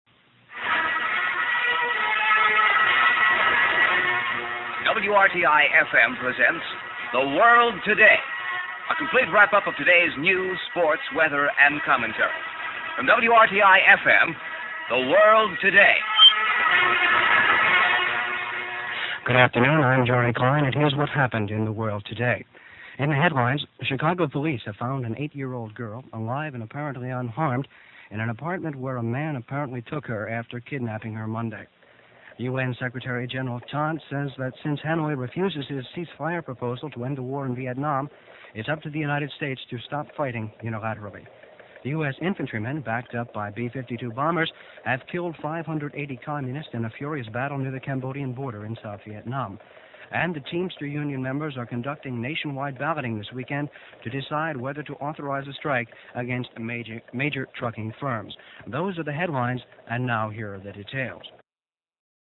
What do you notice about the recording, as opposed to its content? Another portion of "The World Today," with the full intro.